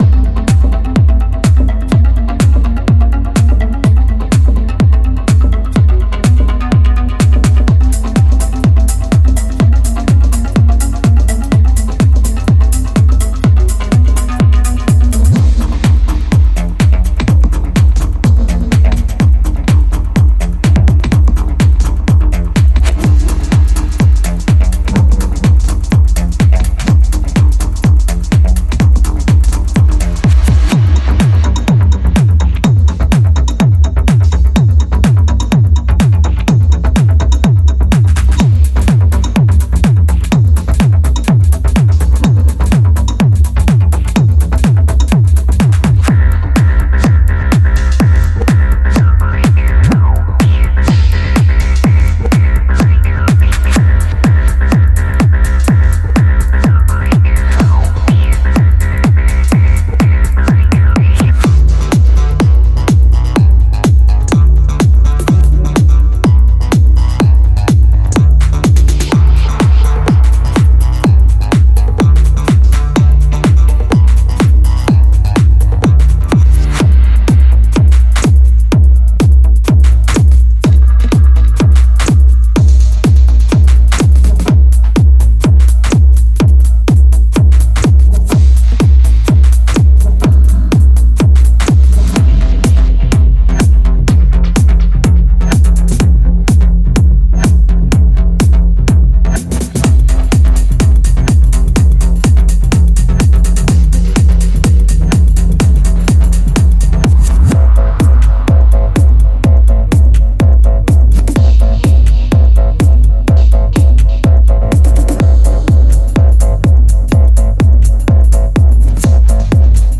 许多胖低音和温暖的合成器，强劲的鼓循环和鼓一声，虚幻的SFX，Vst合成器补丁等等，越来越多！
•436 MB（以125 BPM速度解压缩）
•Tech House